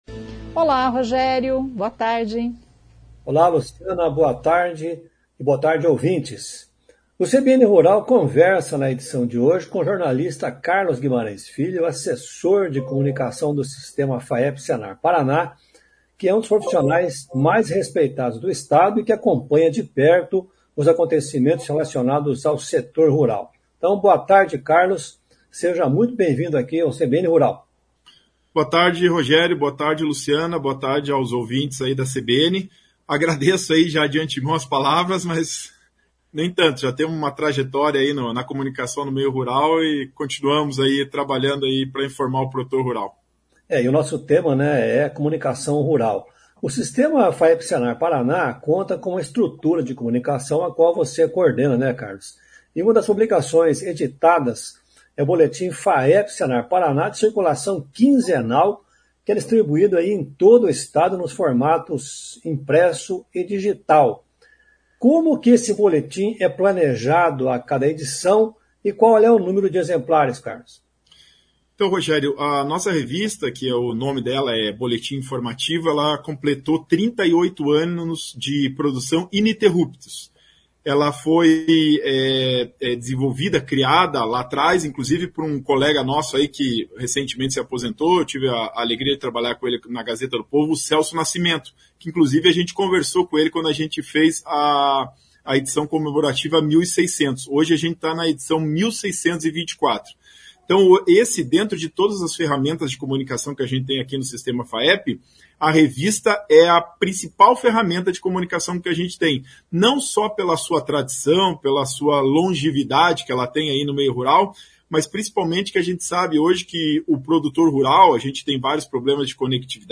conversa com o jornalista